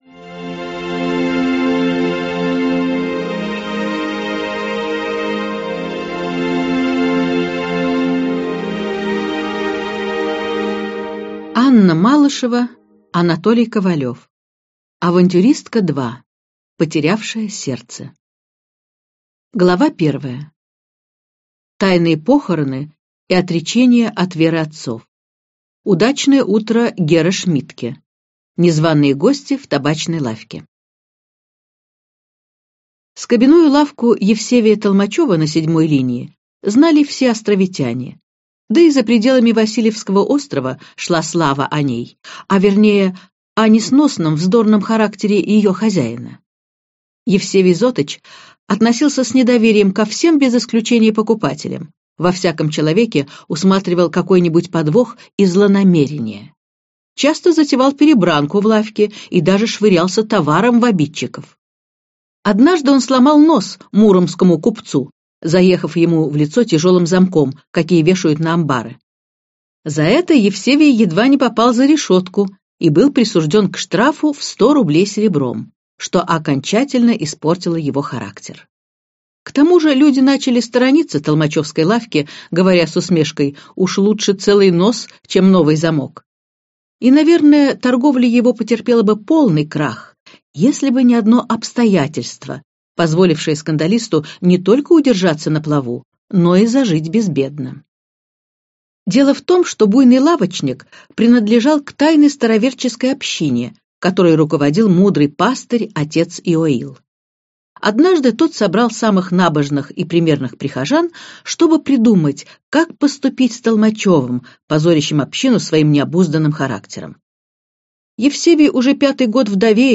Аудиокнига Авантюристка. Потерявшая сердце | Библиотека аудиокниг